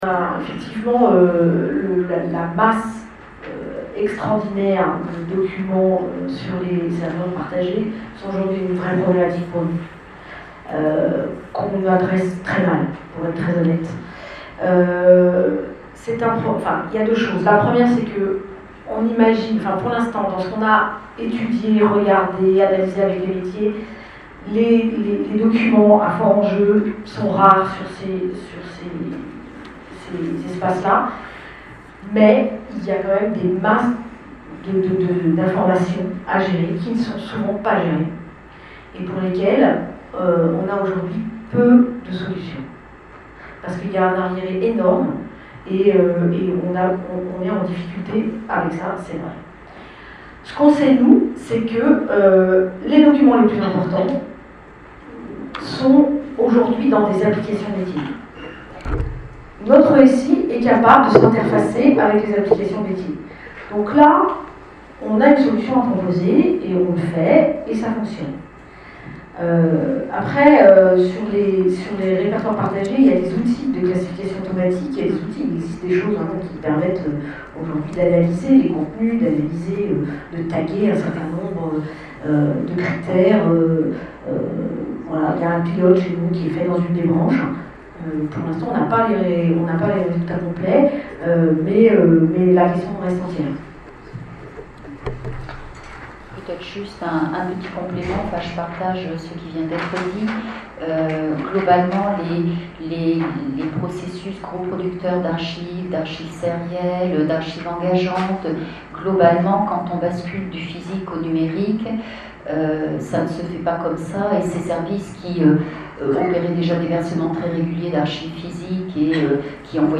Journée d'études 2018 de la section des archivistes d'entreprises et du secteur privé – Association des archivistes français
table_ronde1-partie2.mp3